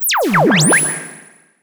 Index of /musicradar/sci-fi-samples/Theremin
Theremin_FX_13.wav